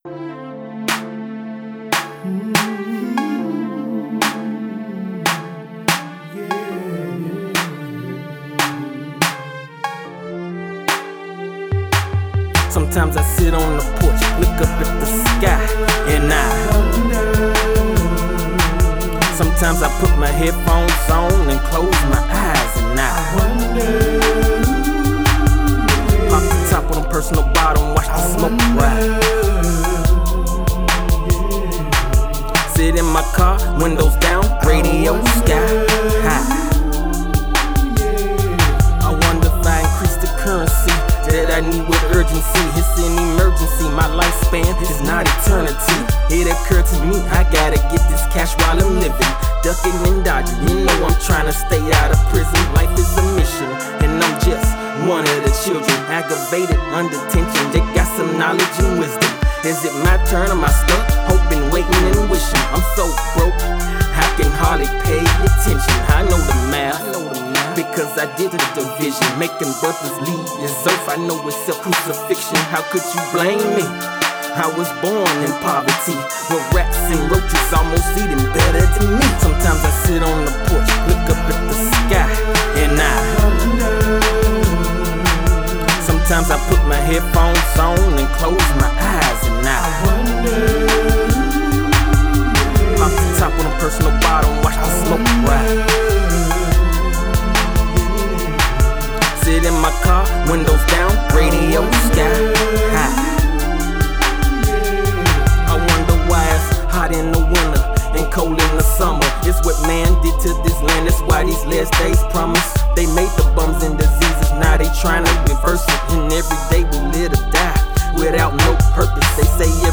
Hip Hop track